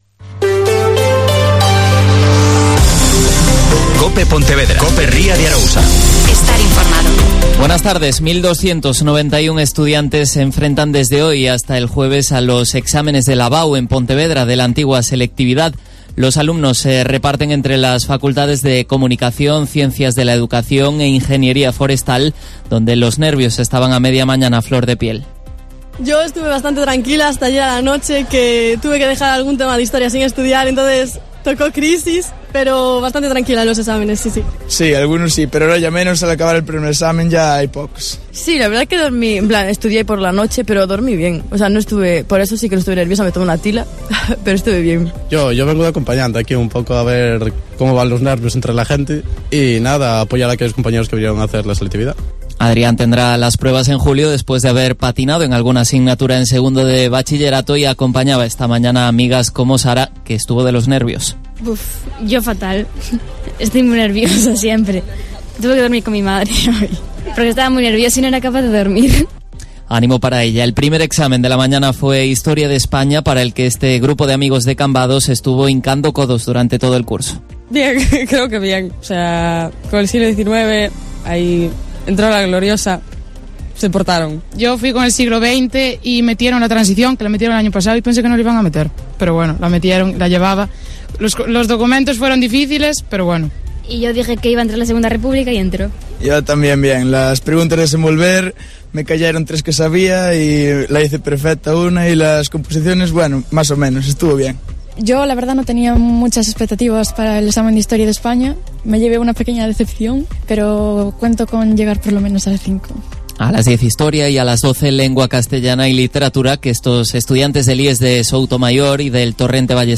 Mediodía COPE Ría de Arosa (Informativo 14:20h)